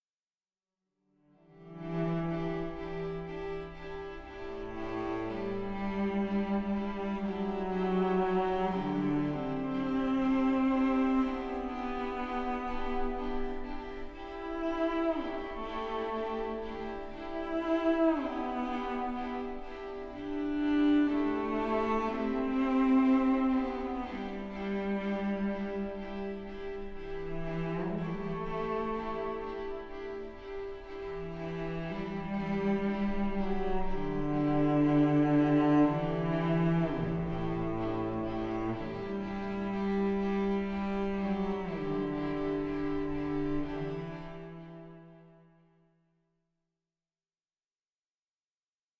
Largo (1.29 EUR)